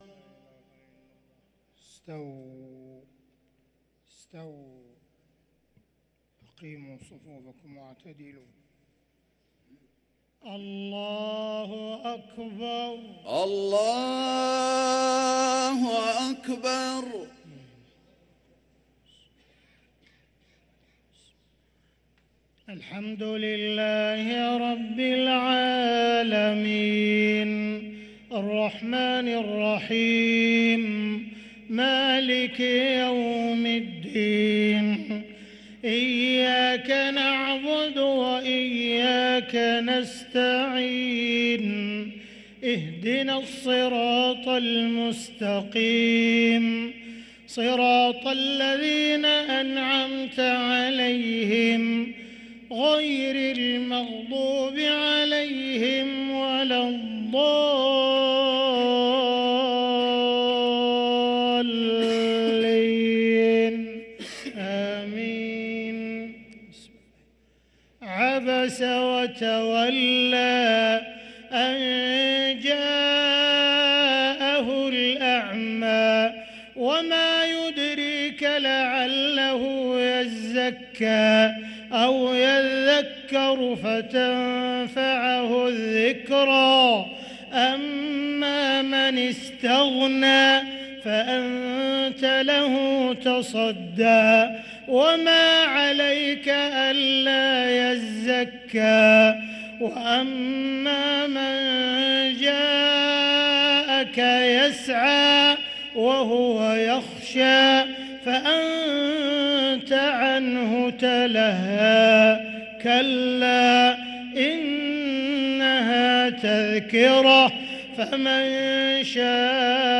صلاة العشاء للقارئ عبدالرحمن السديس 9 رجب 1444 هـ
تِلَاوَات الْحَرَمَيْن .